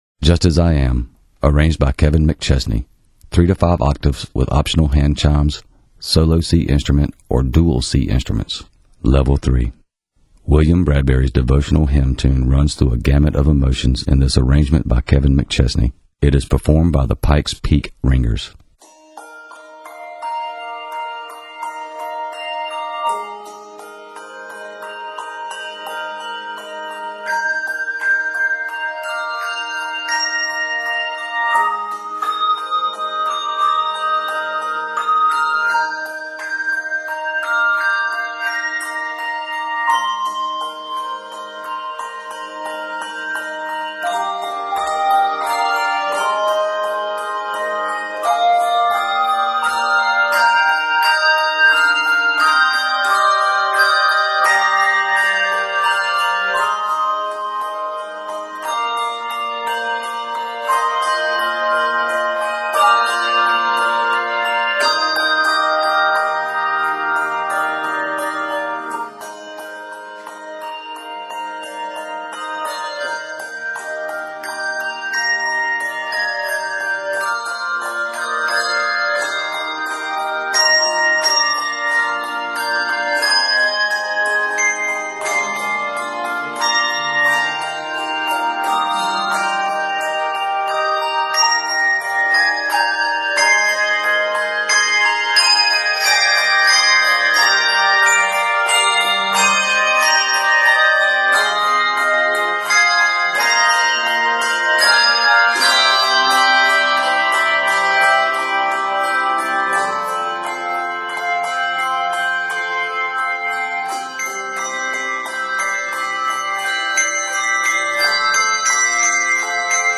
devotional hymn tune
Set in Eb Major and C Major, measures total 111.
Octaves: 3-5